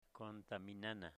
Phonological Representation kontami'nana